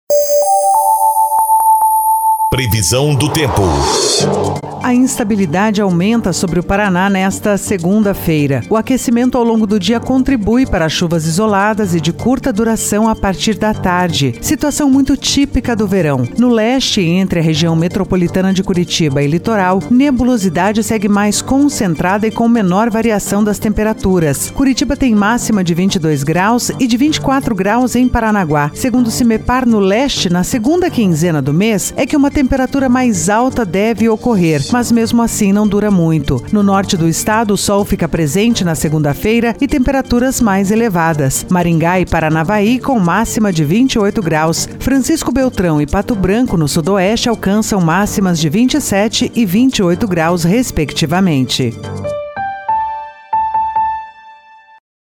PREVISÃO DO TEMPO 09/01/2023